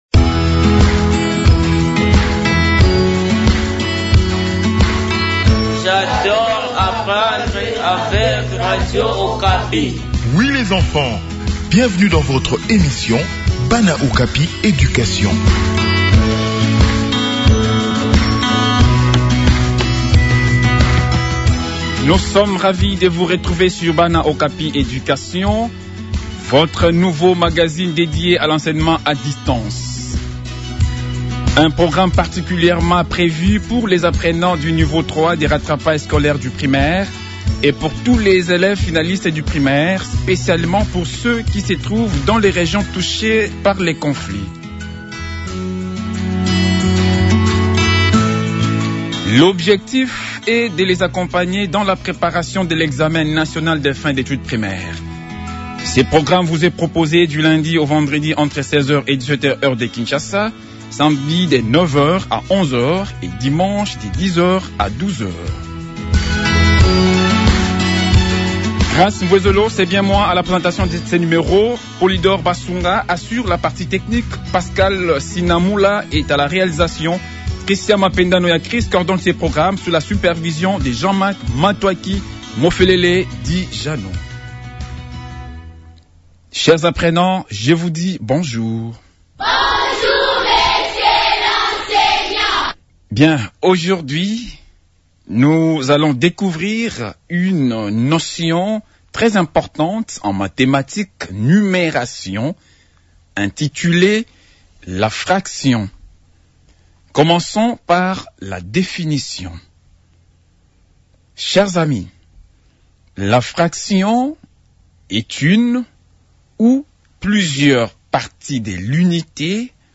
Préparation aux examens nationaux : leçon sur les sortes des fractions en Maths/ Numération